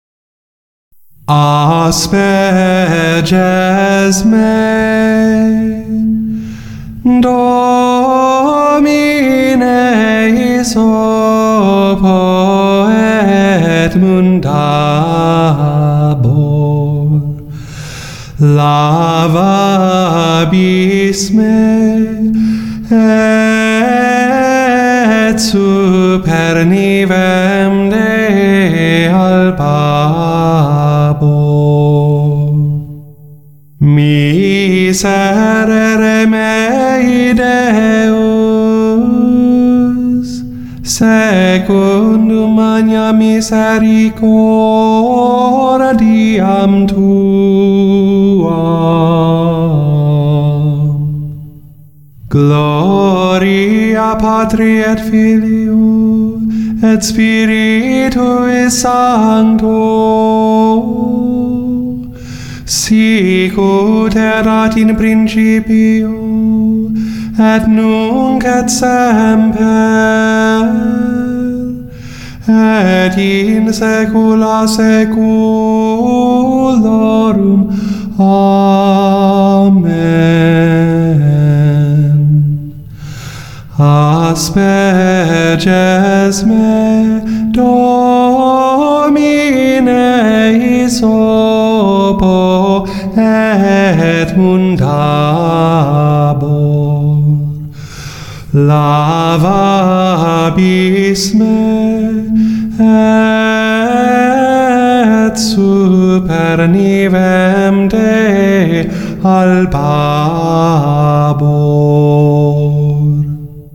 체칠리아 성가대 - As Perges Me (solo and return).mp3
As Perges Me (solo and return).mp3